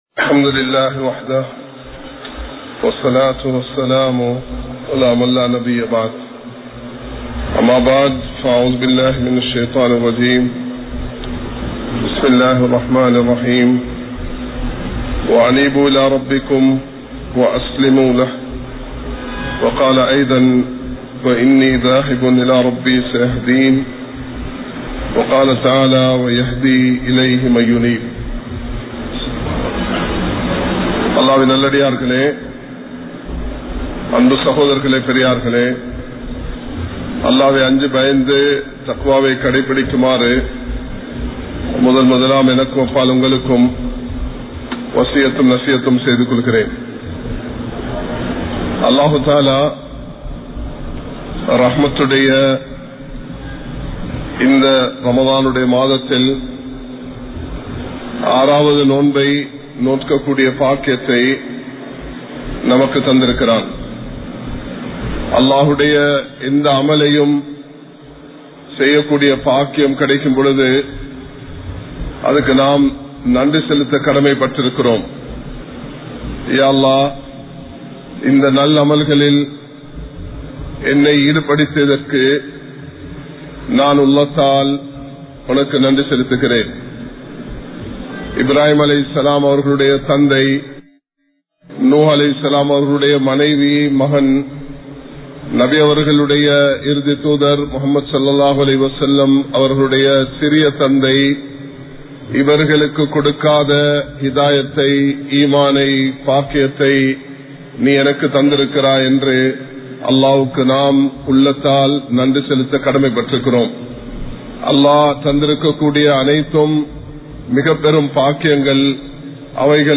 ரமழானும் நாமும் | Audio Bayans | All Ceylon Muslim Youth Community | Addalaichenai
Kollupitty Jumua Masjith